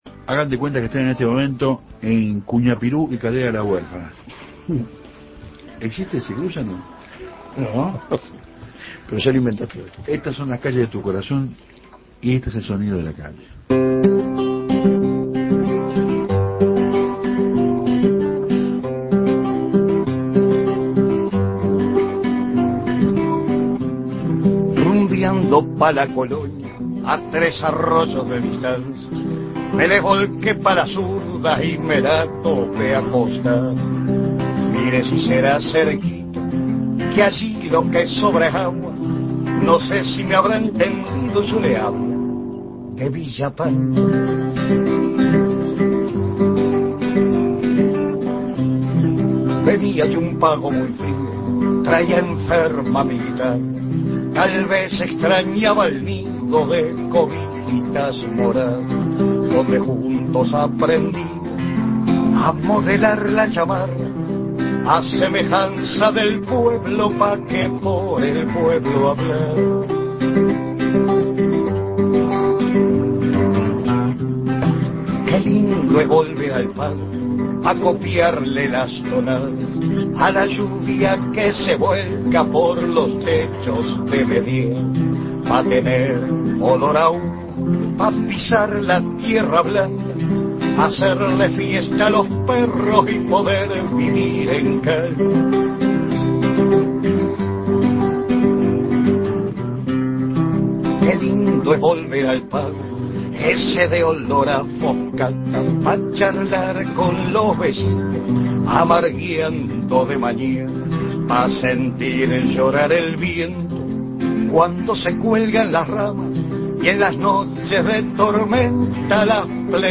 Un encuentro callejero en estudio